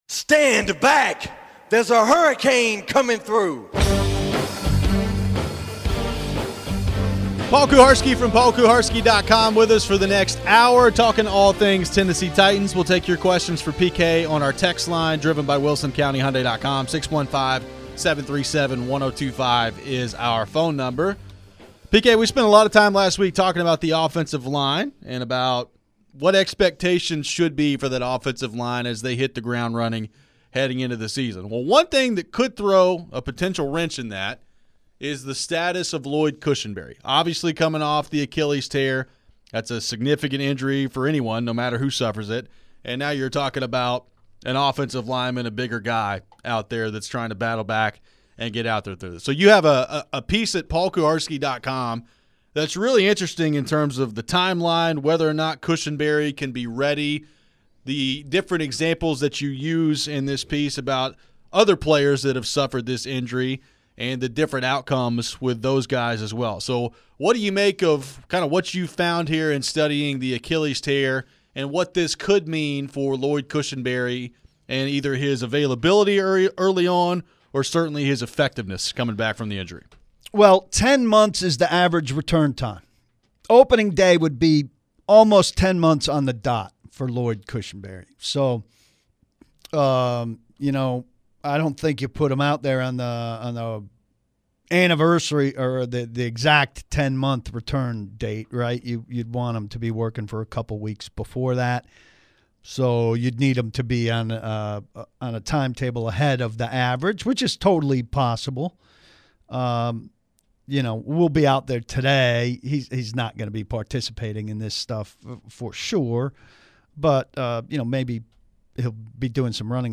in studio talking Titans (5-28-25)